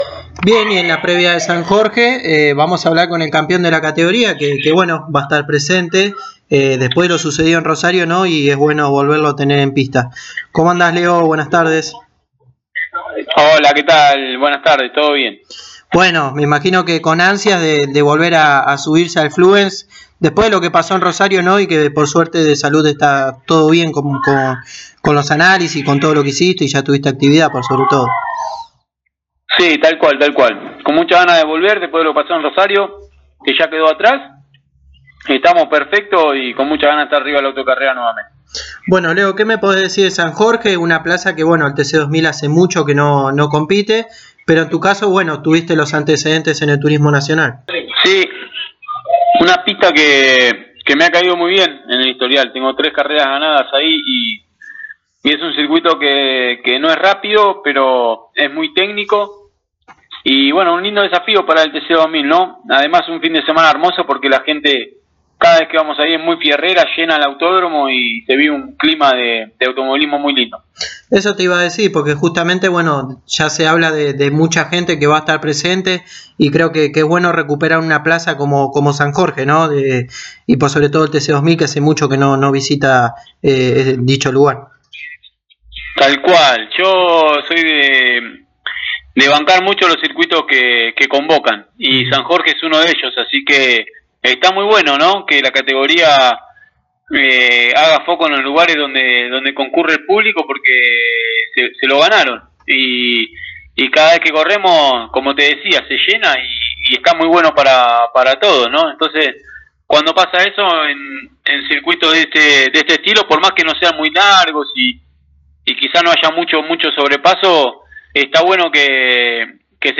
El tandilense pasó por los micrófonos de Pole Position y habló del cambio de equipo que afrontará en el Turismo Carretera a partir de Posadas, volviendo al equipo Las Toscas Racing con un Chevrolet…